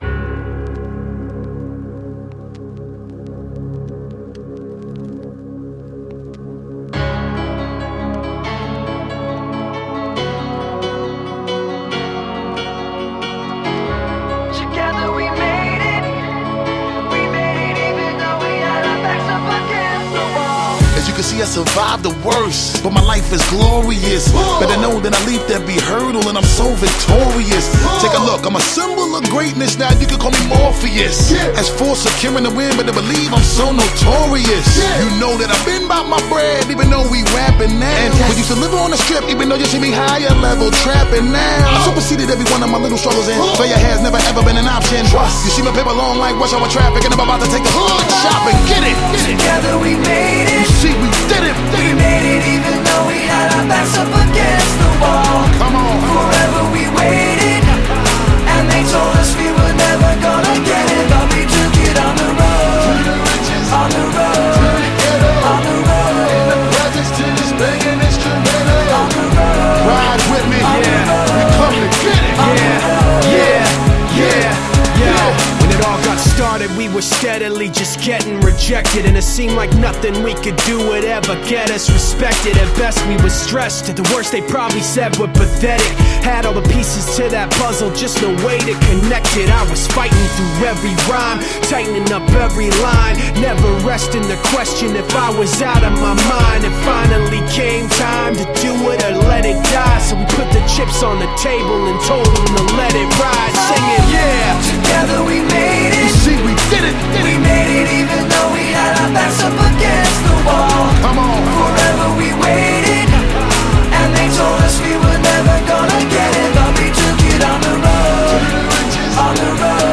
Категория: Реп музыка